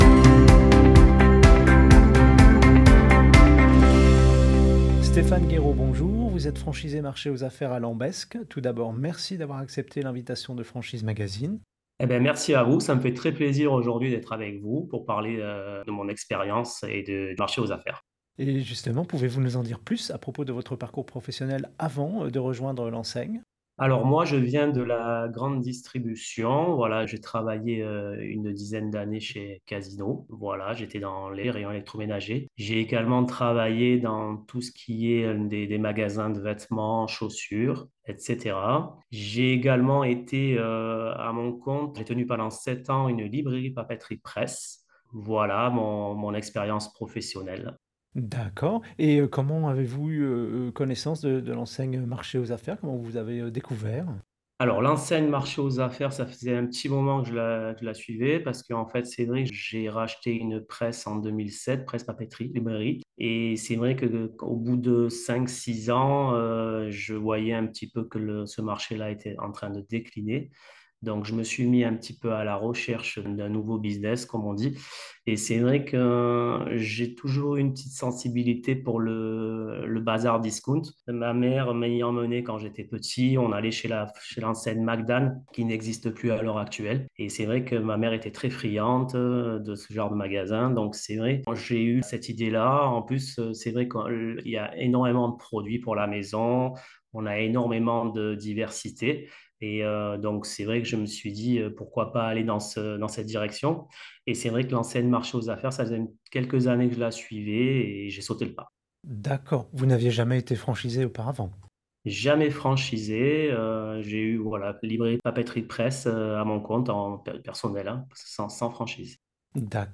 Au micro du podcast Franchise Magazine : la Franchise Marché aux Affaires - Écoutez l'interview